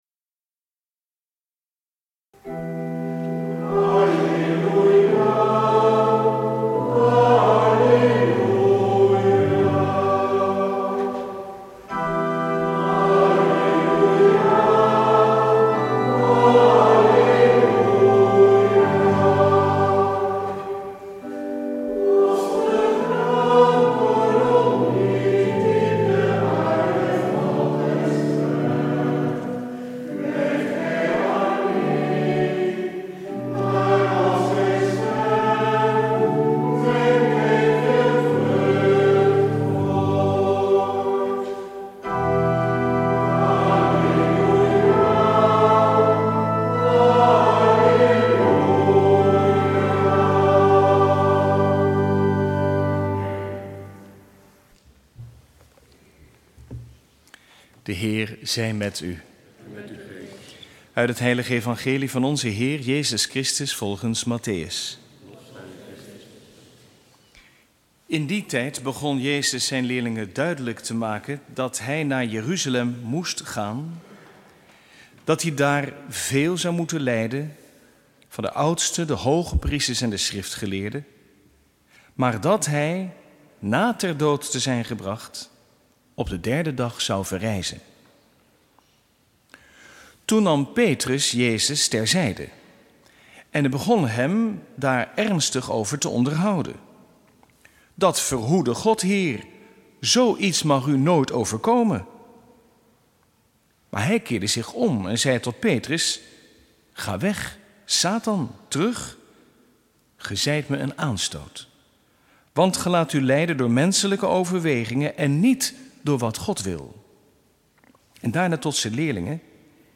Lezingen